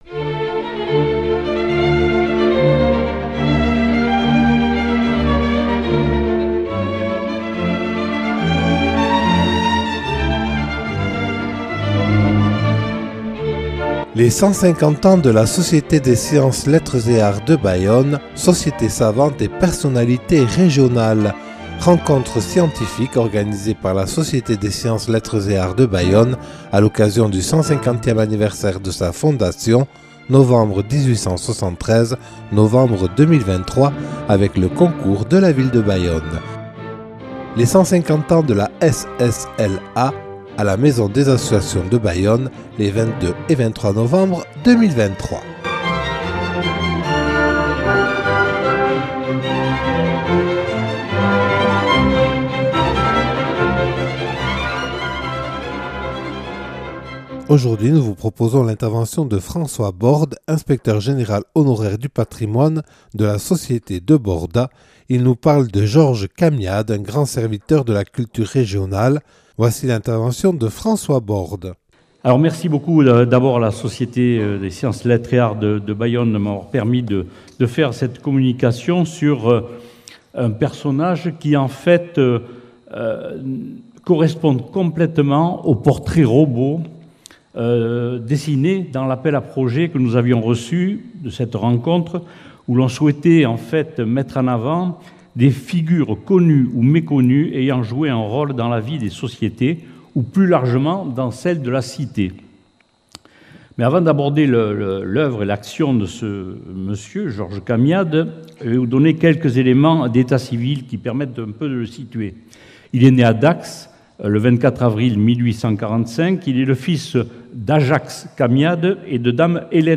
Les 150 ans de la Société des Sciences, Lettres et Arts de Bayonne – (5) – Rencontre scientifique des 22 et 23 novembre 2023